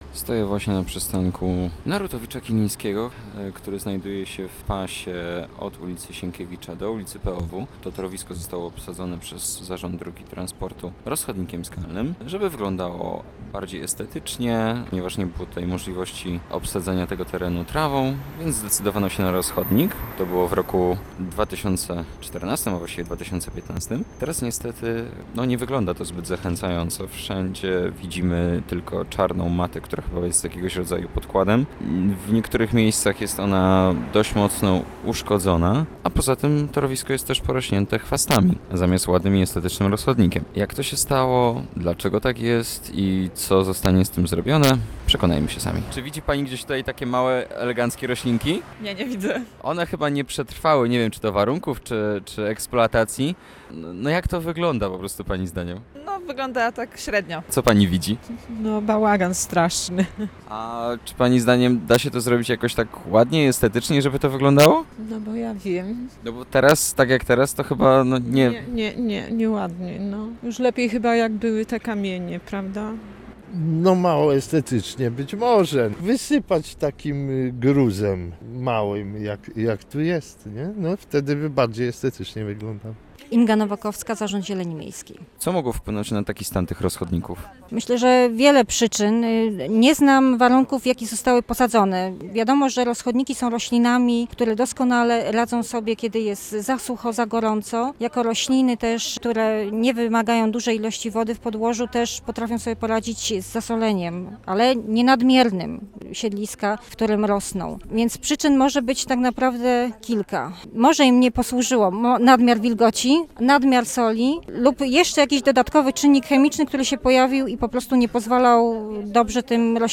Posłuchaj relacji naszego reportera i dowiedz się więcej: Nazwa Plik Autor Rozchodniki audio (m4a) audio (oga) ZDJĘCIA, NAGRANIA WIDEO, WIĘCEJ INFORMACJI Z ŁODZI I REGIONU ZNAJDZIESZ W DZIALE “WIADOMOŚCI”.